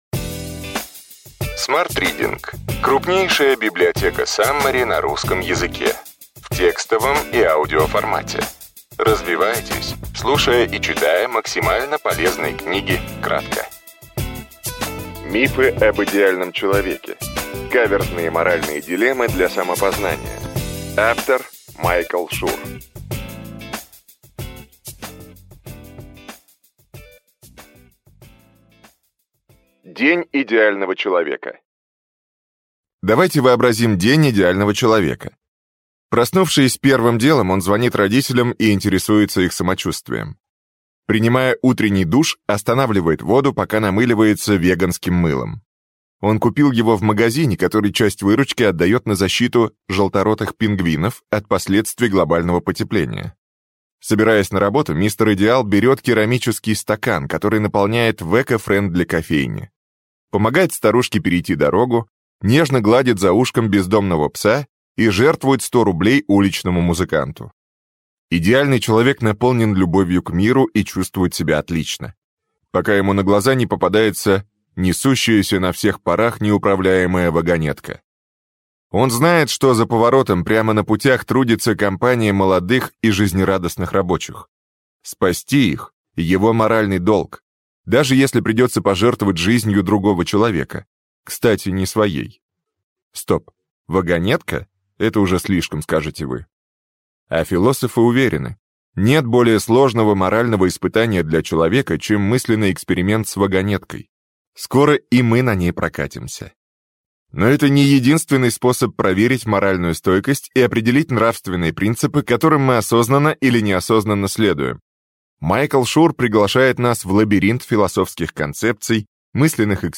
Аудиокнига Мифы об идеальном человеке. Каверзные моральные дилеммы для самопознания.
Прослушать и бесплатно скачать фрагмент аудиокниги